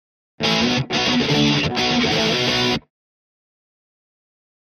Guitar Rock Finale Rhythm Version 2